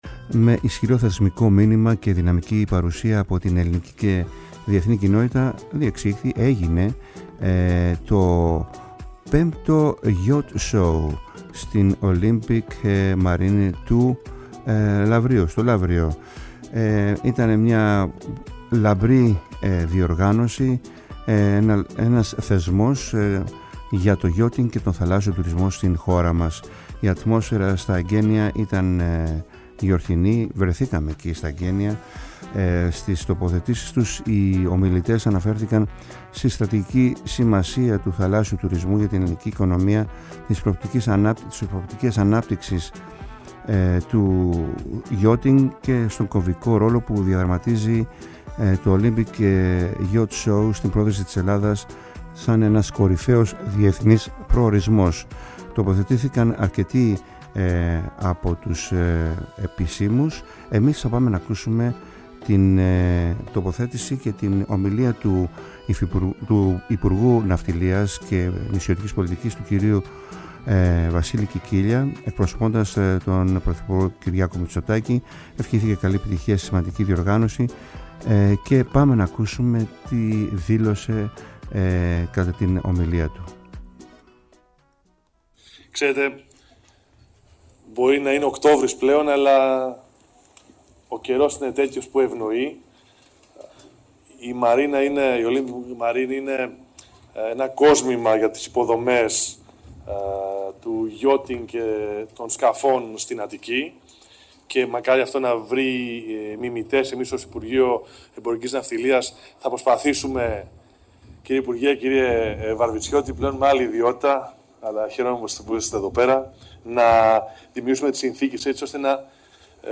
Ακούμε στις «ΚΑΛΕΣ ΘΑΛΑΣΣΕΣ» και τη ΦΩΝΗ ΤΗΣ ΕΛΛΑΔΑΣ της δηλώσεις του  Υπουργού Ναυτιλίας και Νησιωτικής Πολιτικής, Βασίλη Κικίλια, ο οποίος παραβρέθηκε στα εγκαίνια του 5ου Olympic Yacht Show στην Olympic Marine στο Λαύριο.